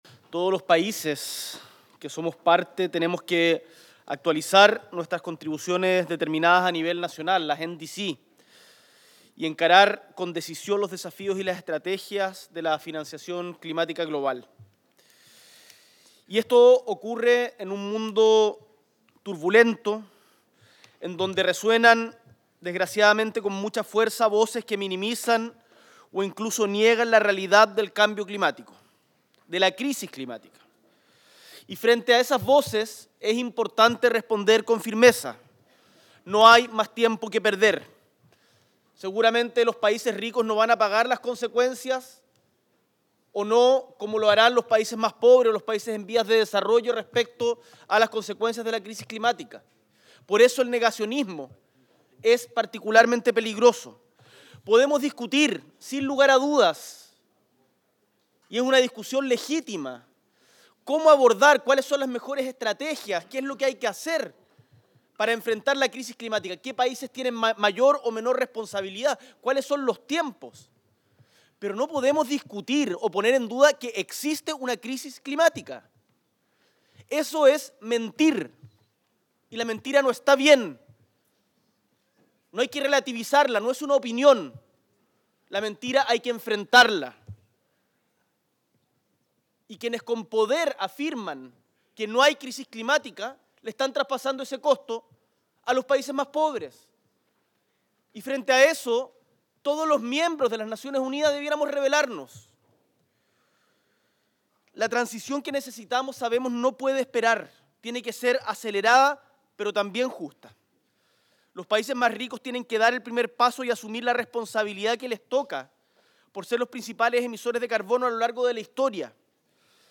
S.E. el Presidente de la República, Gabriel Boric Font, participa de la Cumbre sobre el Clima 2025